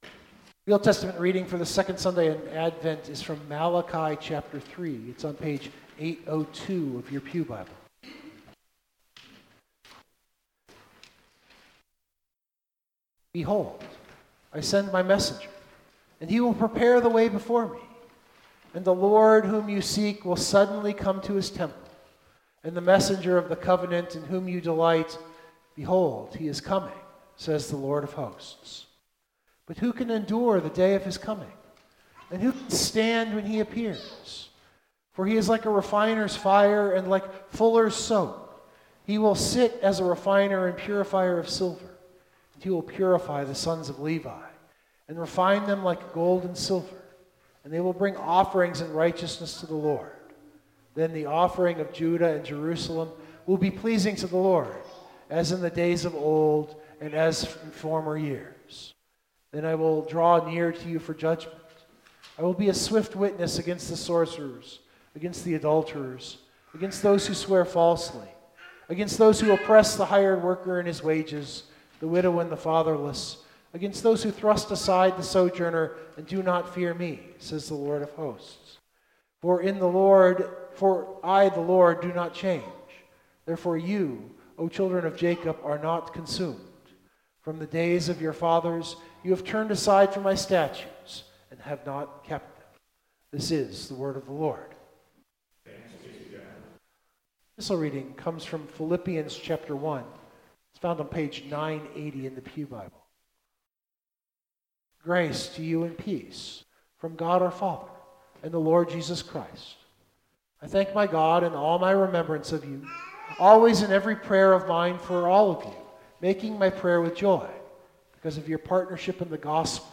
Music Note: I left in the Hymn of Day.